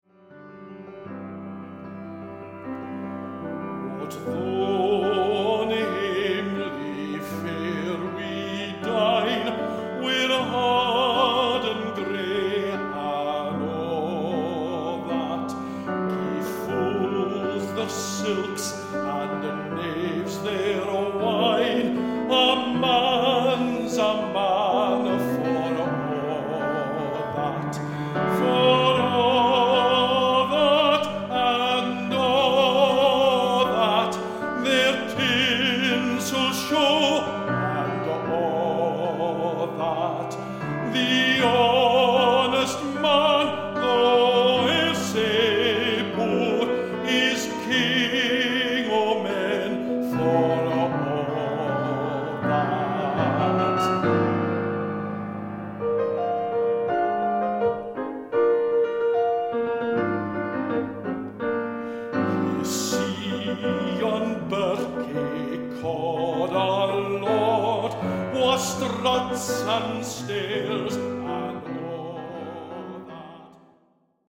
Voicing: Medium Voice